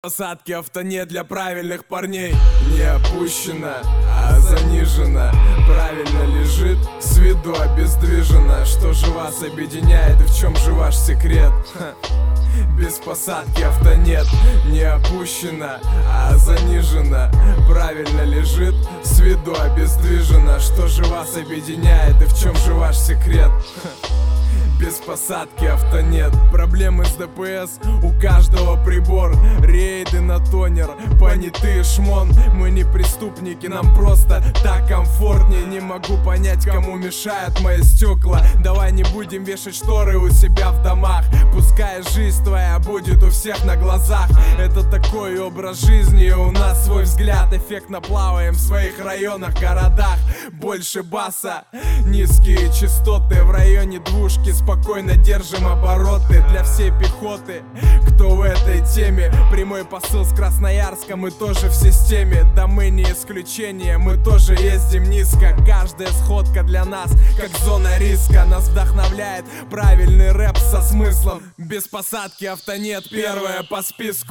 рэп
Хип-хоп
речитатив